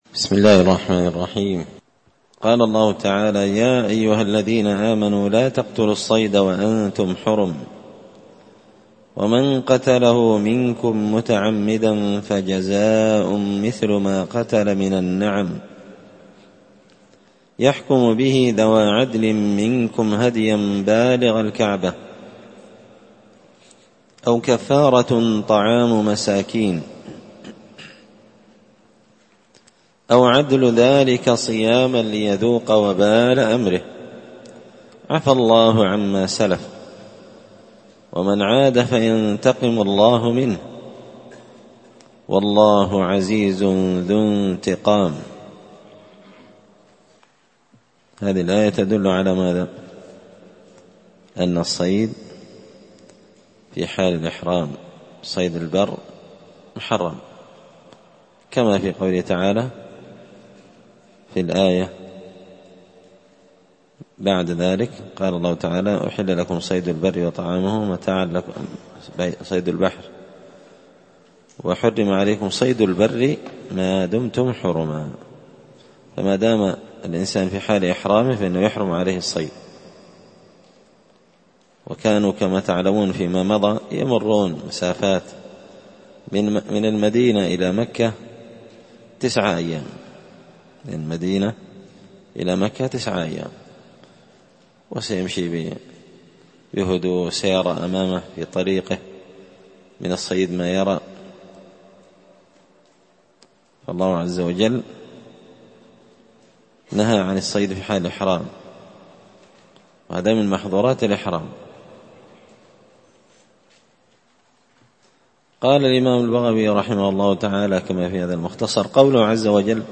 مسجد الفرقان قشن_المهرة_اليمن
مختصر تفسير الإمام البغوي رحمه الله الدرس 282